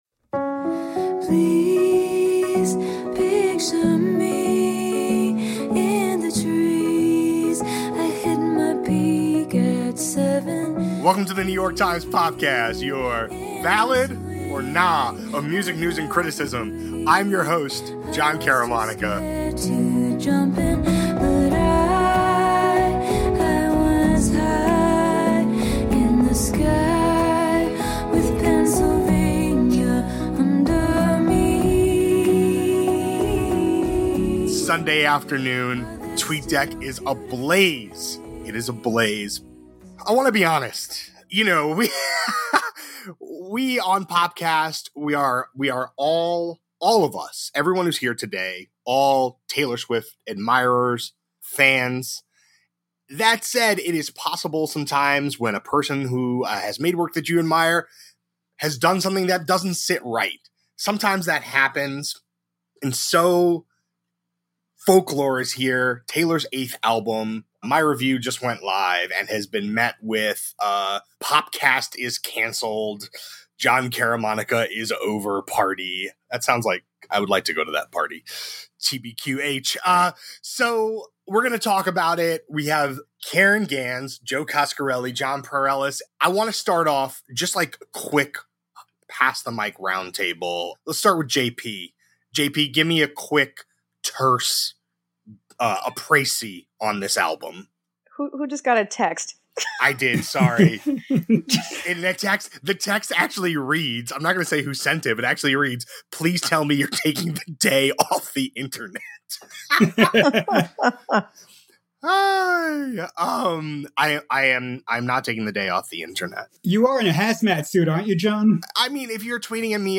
A conversation about what “Folklore” portends for Taylor Swift’s musical evolution.